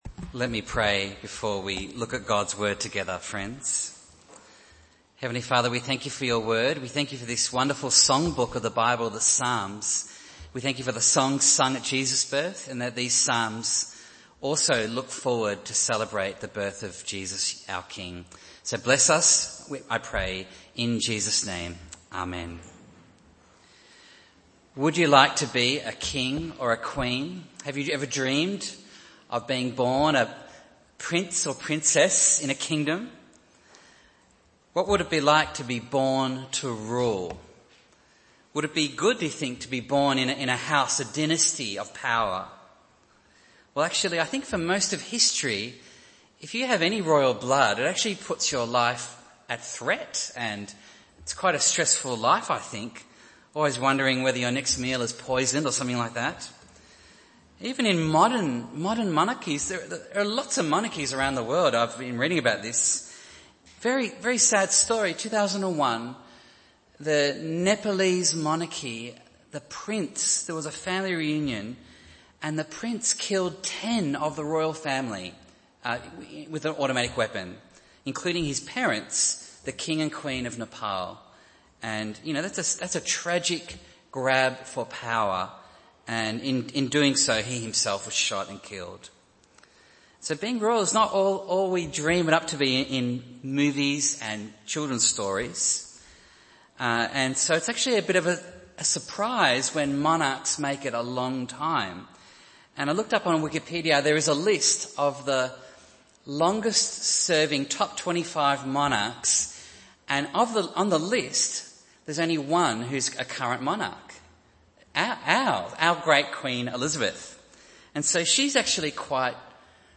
Passage: Psalm 132 Service Type: Sunday Morning